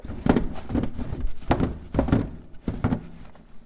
卫生间 " 卫生间用纸
Tag: 冲洗 厕所 厕所